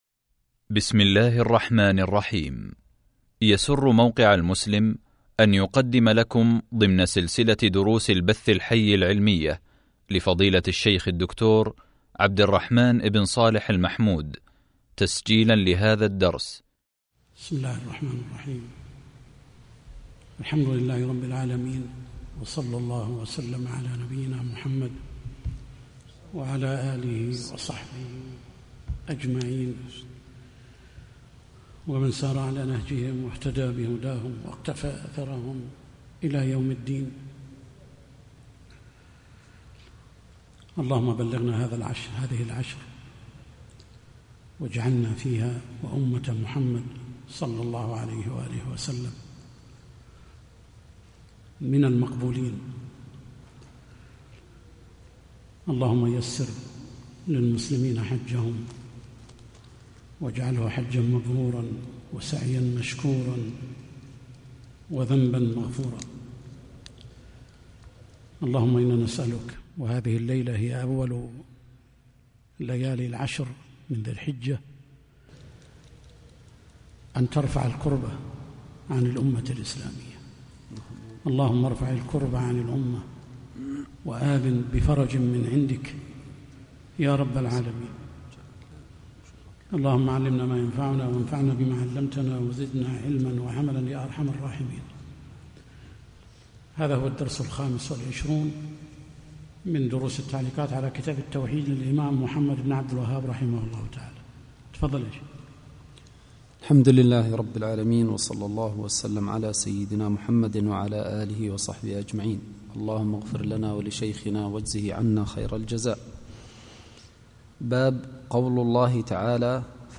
شرح كتاب التوحيد | الدرس 25 | موقع المسلم